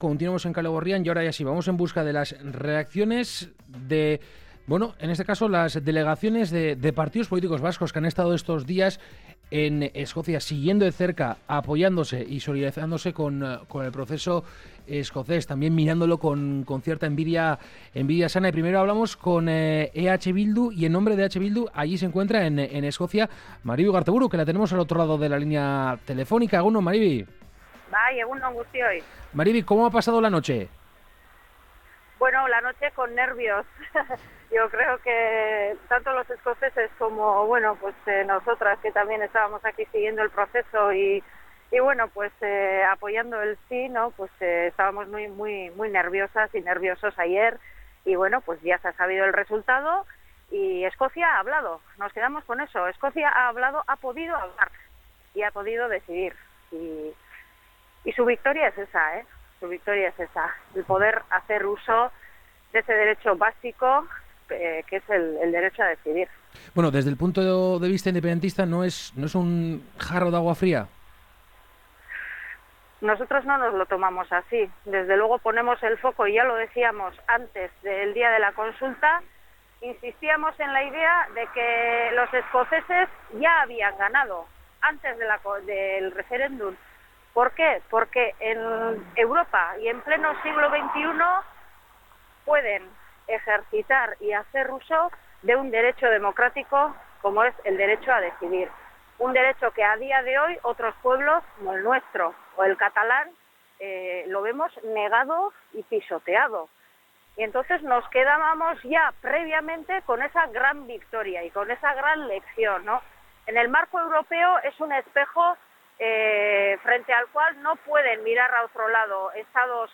Nosotros hemos pedido la opinión a Maribi Ugarteburu, parte de esa delegación de la coalicion soberanista.